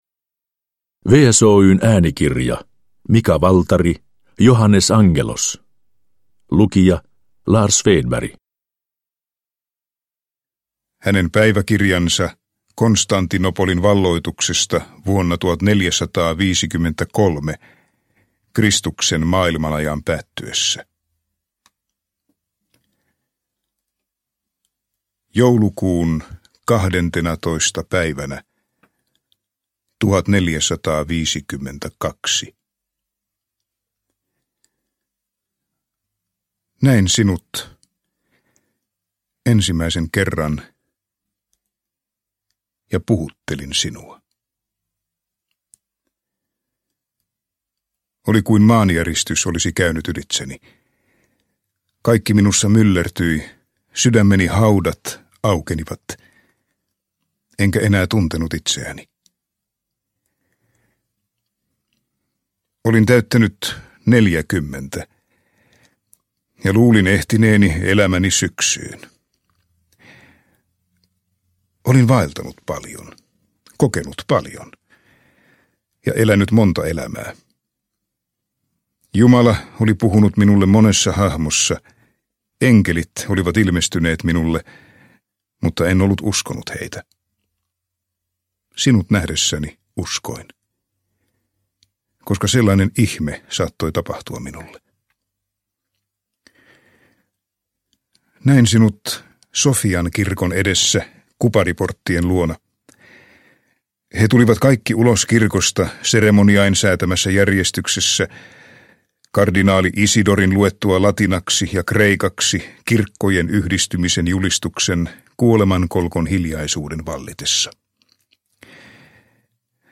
Johannes Angelos – Ljudbok – Laddas ner